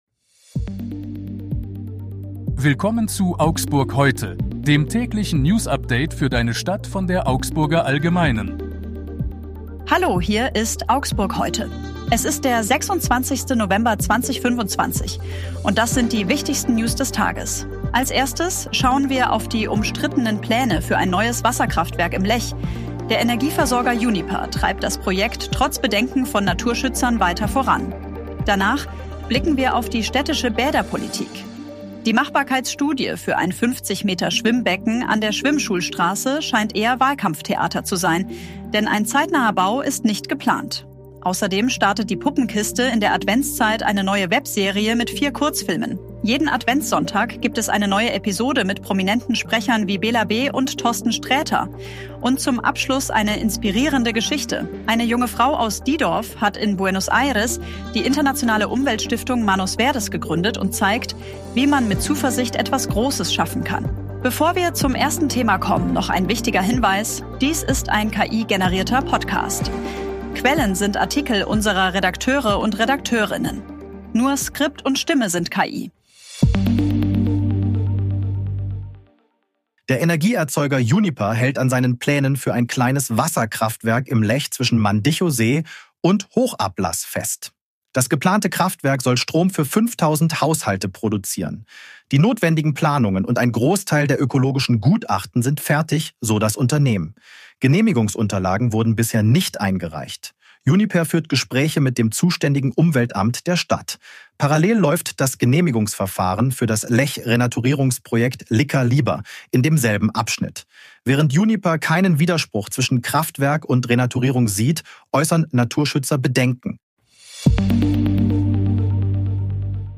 Hier ist das tägliche Newsupdate für deine Stadt.
hat Dies ist ein KI-generierter Podcast.
Nur Skript und Stimme sind